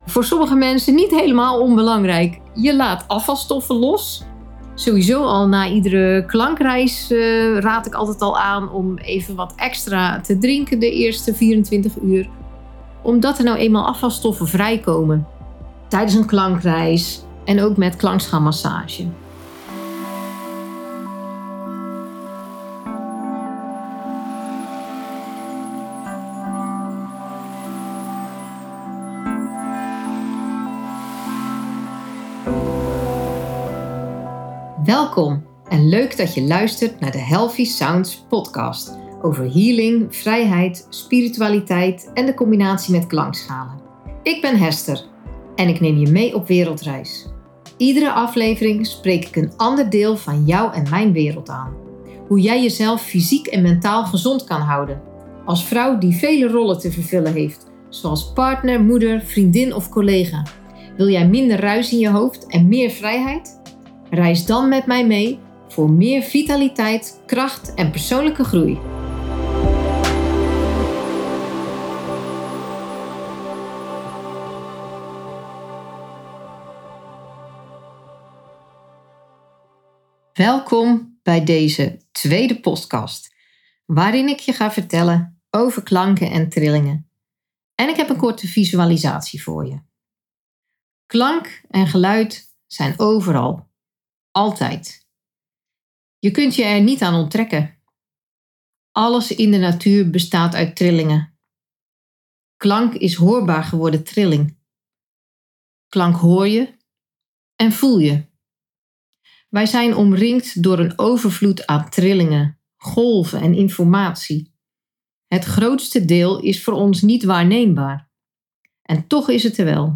Een korte visualisatie om je gedachten te bevrijden van de kleine zorgen.